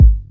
Game Kick.wav